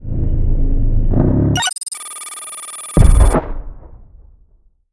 escape1.ogg